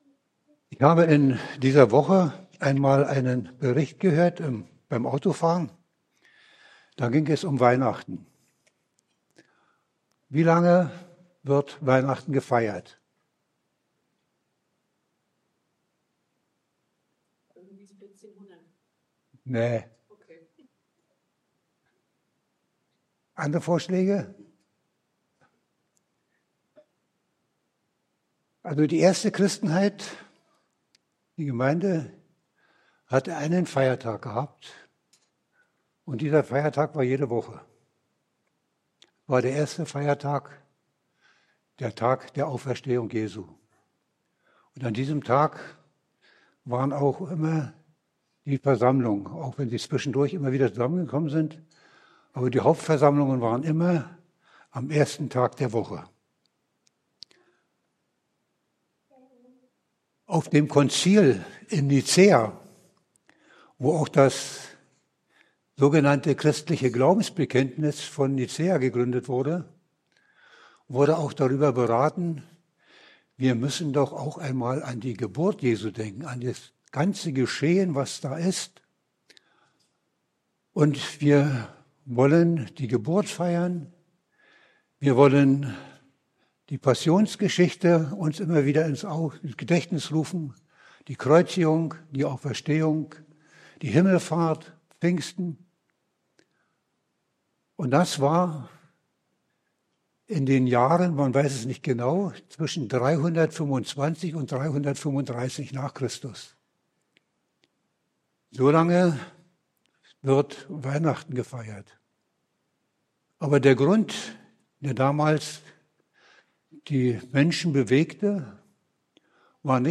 Baptisten Bützow: Predigten